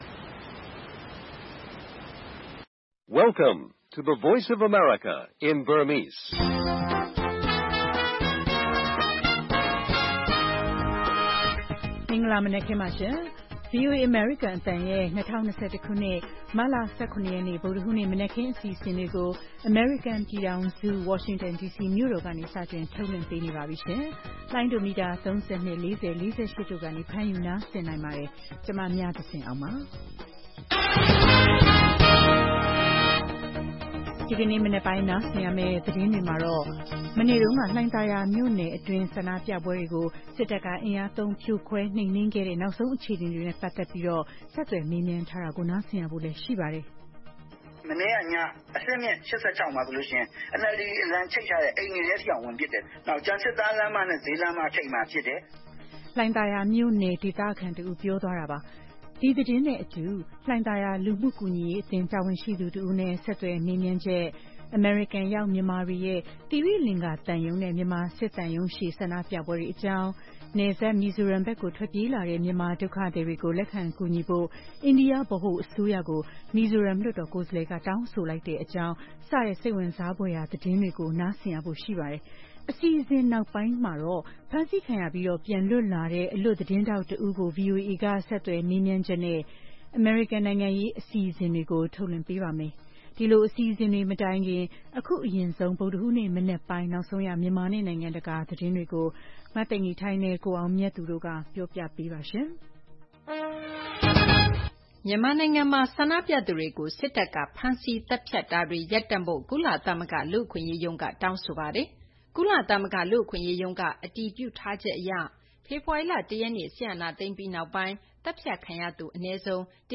ဒီကနေ့အစီအစဉ်မှာတော့ နောက်ဆုံးရနိုင်ငံတကာသတင်းတွေနဲ့အတူ - ရန်ကုန်တိုင်း လှိုင်သာယာ မြို့နယ်တွင်းကနောက်ဆုံးအခြေအနေတွေနဲ့ ပတ်သက်လို့ ဒေသခံတွေနဲ့ ဆက်သွယ်မေးမြန်းချက်၊ အမေရိကန်ရောက်မြန်မာတွေရဲ့ သိရိလင်္ကာသံရုံးနဲ့ မြန်မာစစ်သံရုံးရှေ့ ဆန္ဒပြပွဲတွေ အကြောင်း၊ နယ်စပ် မီဇိုရမ်ဘက်ကို ထွက်ပြေးလာတဲ့ မြန်မာဒုက္ခသည်တွေကို လက်ခံကူညီဖို့ အိန္ဒိယ ဗဟိုအစိုးရကို မီဇိုရမ် လွှတ်တော်ကိုယ်စားလှယ်က တောင်းဆိုလိုက်တဲ့အကြောင်း စတဲ့ စိတ်ဝင်စားဖွယ်ရာ သတင်းတွေကို နားဆင်ရပါမယ်။ အစီအစဉ်နောက်ပိုင်းမှာတော့ ဆန္ဒပြပွဲတွေအတွင်း ဖမ်းဆီးခံရပြီး ပြန်လွတ်လာတဲ့ အလွတ်သတင်းထောက်တစ်ဦးကို VOA က ဆက်သွယ်မေးမြန်းချက်နဲ့ အမေရိကန် နိုင်ငံရေး အစီအစဉ်ကိုလည်း ထုတ်လွှင့်ပေးပါမယ်။